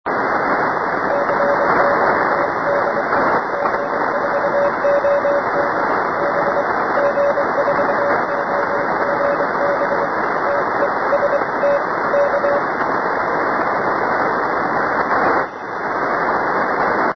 CQ TEST recepci�n con mucho QRN en CW 7.019KHZ